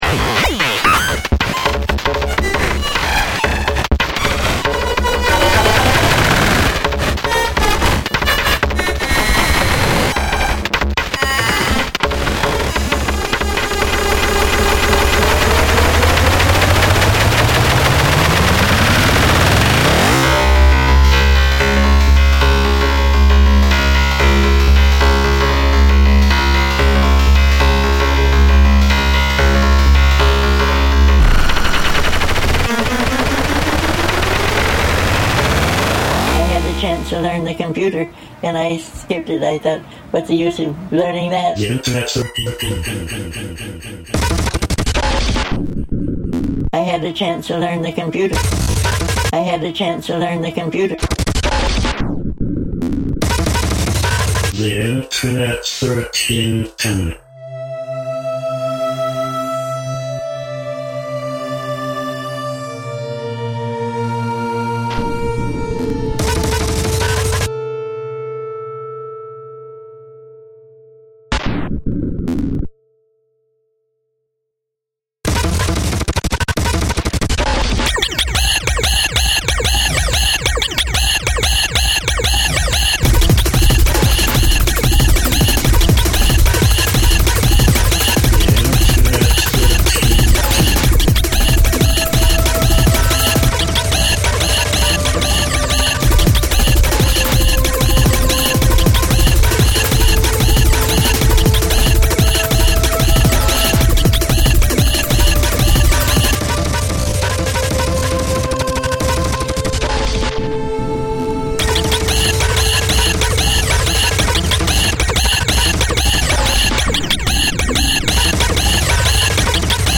dance/electronic
IDM